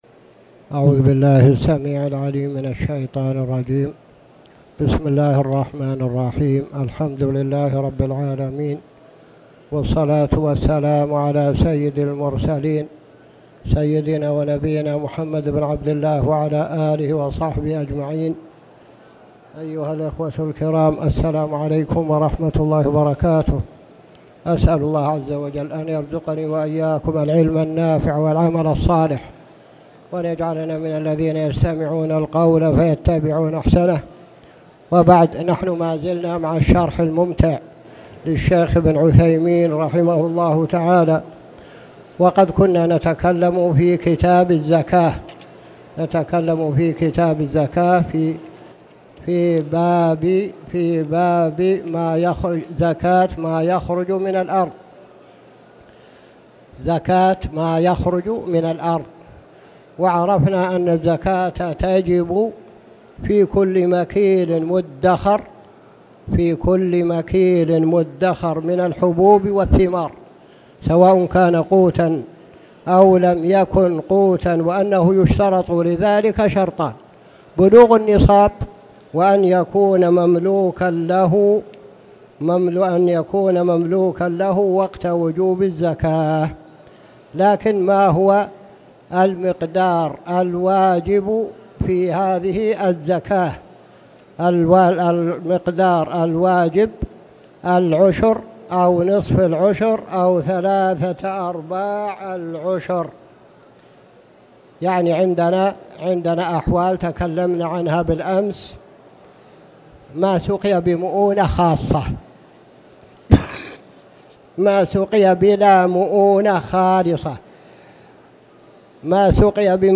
تاريخ النشر ١٥ جمادى الآخرة ١٤٣٨ هـ المكان: المسجد الحرام الشيخ